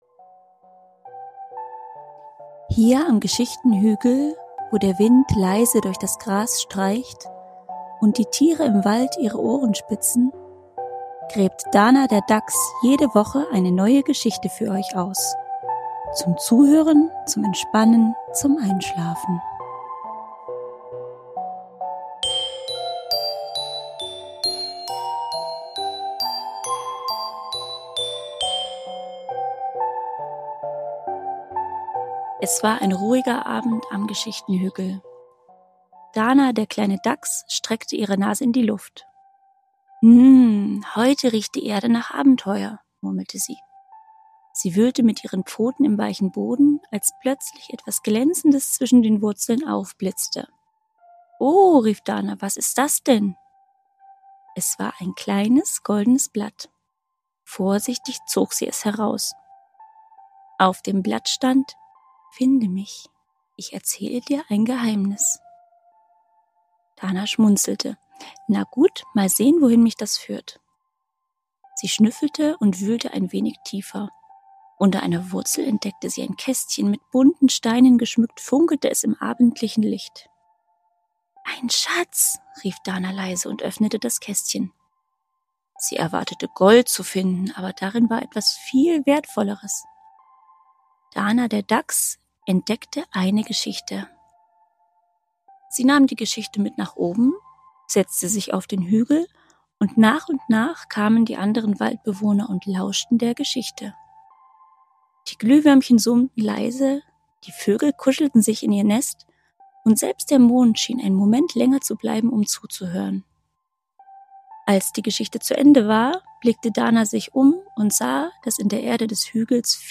Ruhige Geschichten für Kinder – zum Entspannen, Zuhören und Einschlafen.